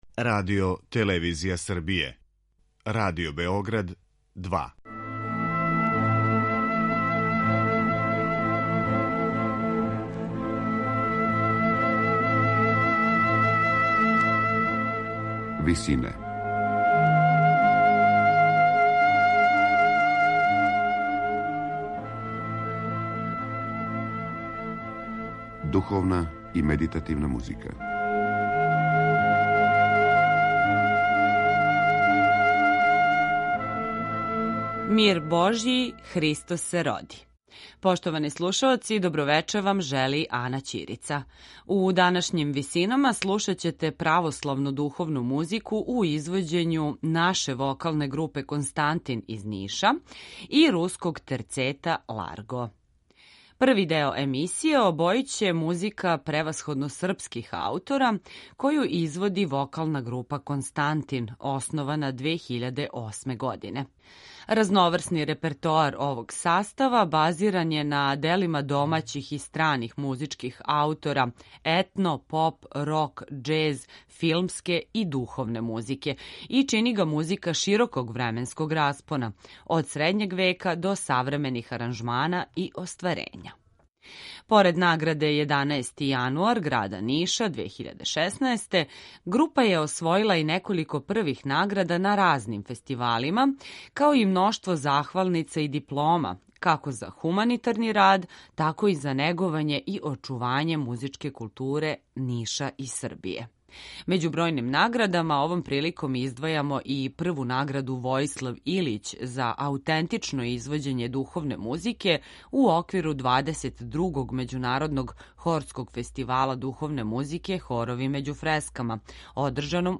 професионалног мушког терцета